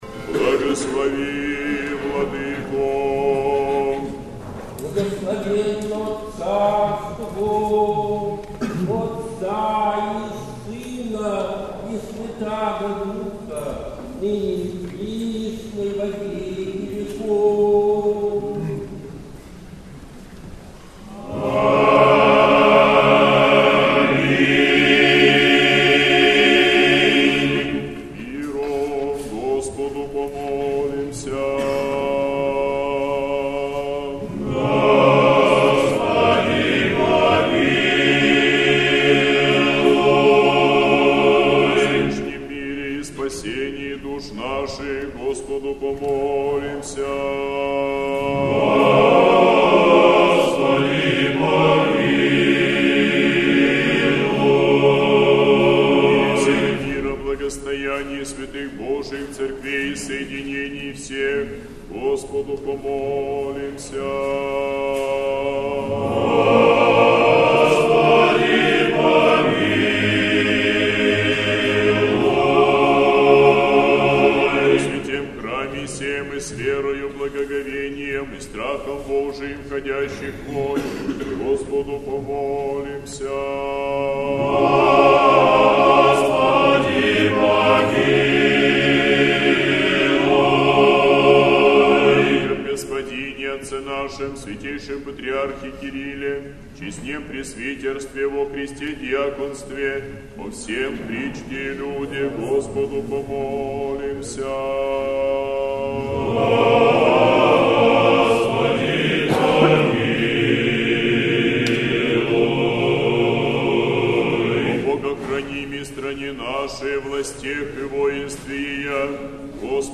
Сретенский монастырь. Божественная литургия. Хор Сретенского монастыря.